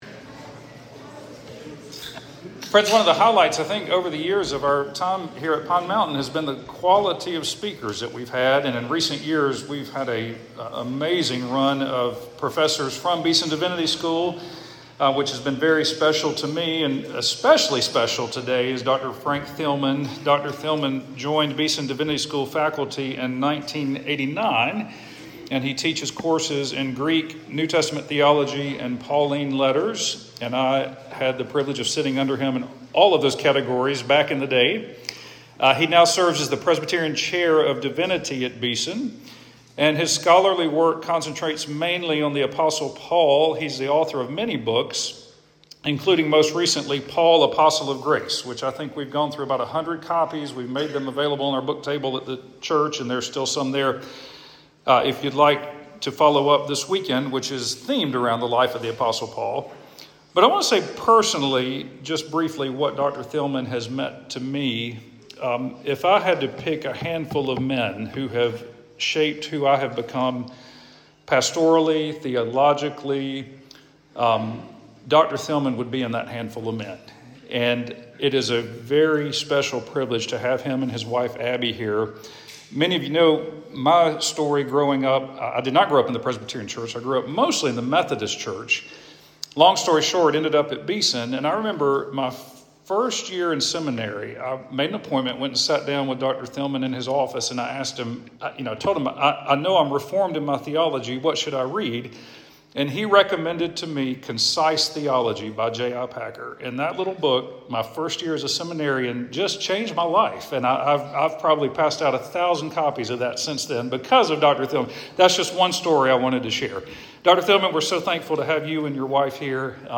Paul: Who Was the Apostle Paul? Sermon